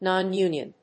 音節nòn・únion 発音記号・読み方
/nɑˈnjunjʌn(米国英語), nɑ:ˈnju:njʌn(英国英語)/